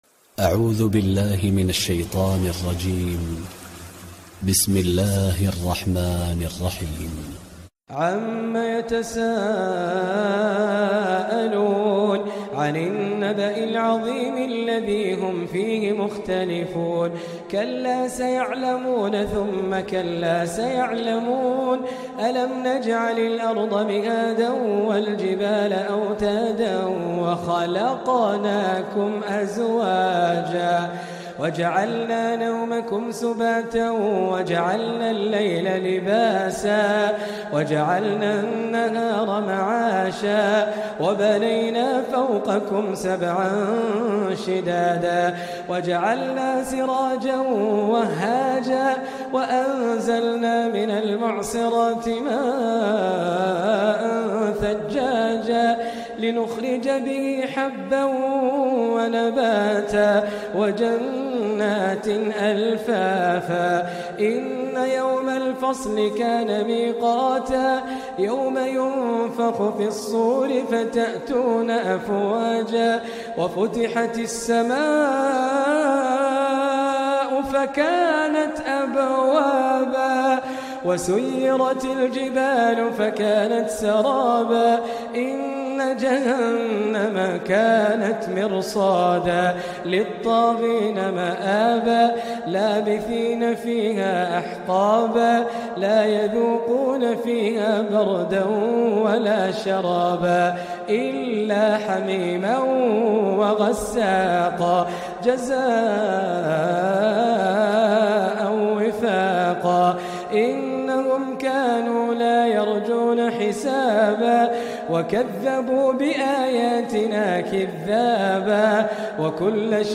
تلاوات – تلاوات القارئ خالد الجليل
تلاوات